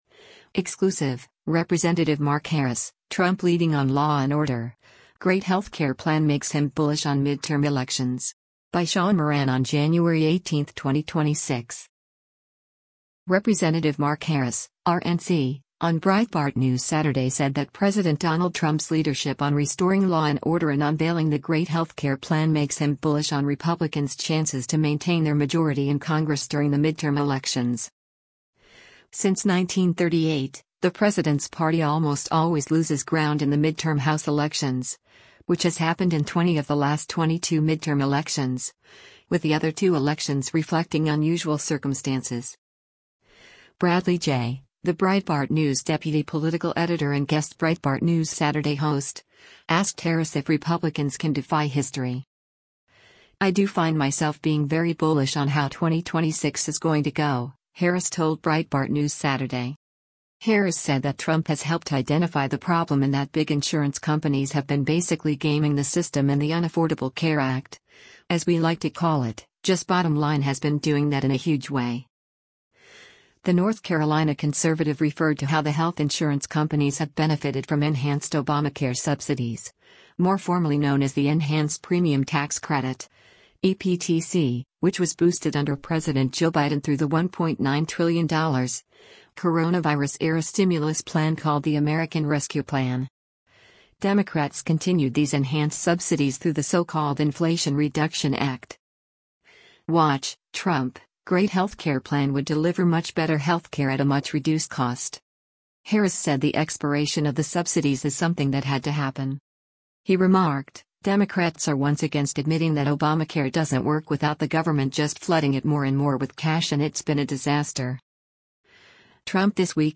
Exclusive — Rep. Mark Harris: Trump Leading on Law and Order, Great Healthcare Plan Makes Him ‘Bullish’ on Midterm Elections
Rep. Mark Harris (R-NC) on Breitbart News Saturday said that President Donald Trump’s leadership on restoring law and order and unveiling the Great Healthcare Plan makes him “bullish” on Republicans’ chances to maintain their majority in Congress during the midterm elections.